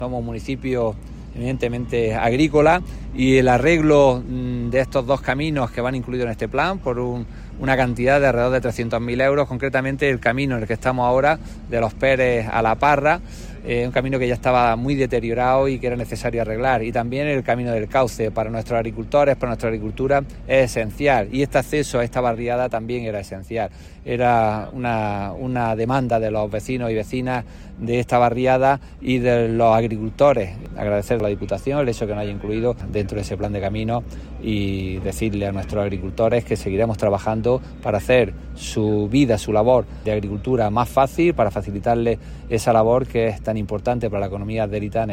Audio-Alcalde.mp3